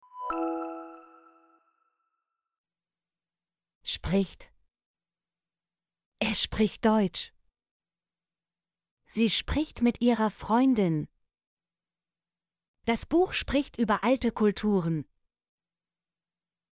spricht - Example Sentences & Pronunciation, German Frequency List